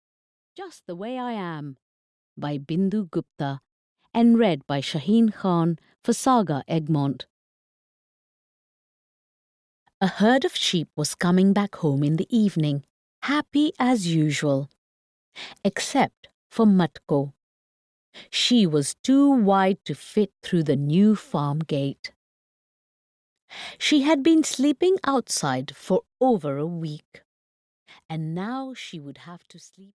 Just the Way I Am (EN) audiokniha
Ukázka z knihy